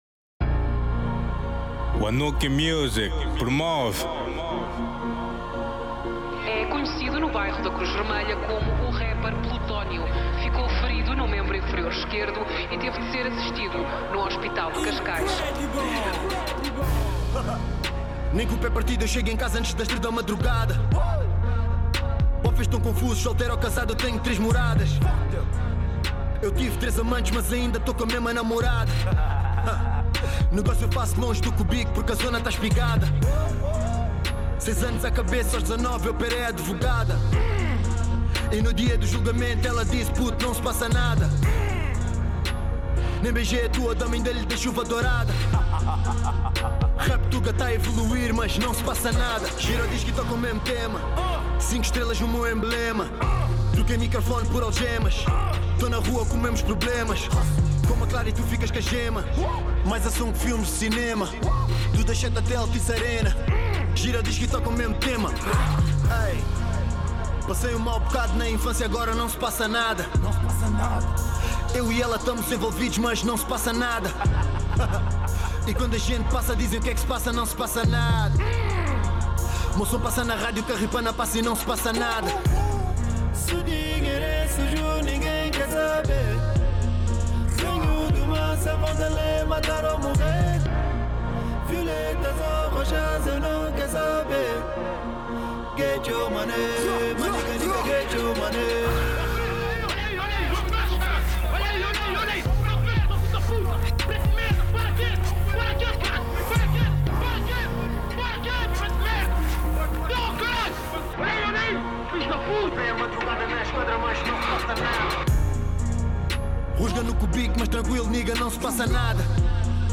Genero: Rap